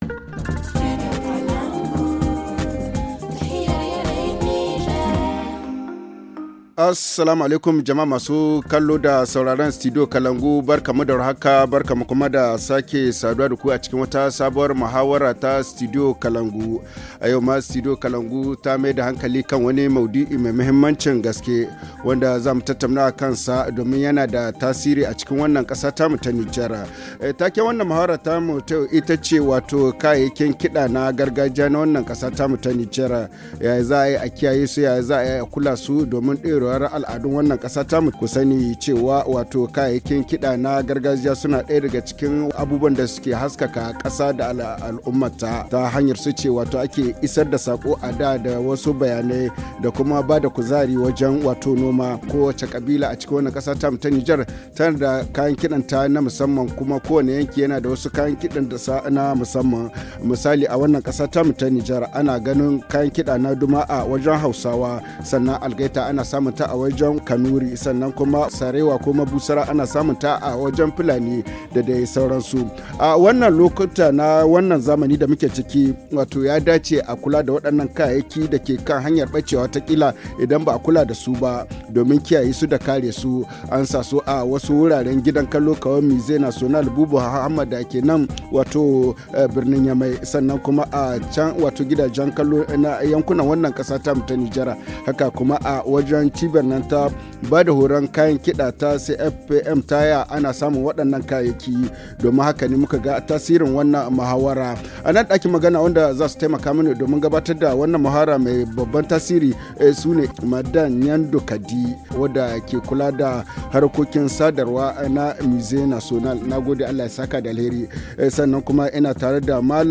[Forum en haoussa] Comment se fait la conservation des instruments de musique traditionnels du Niger ?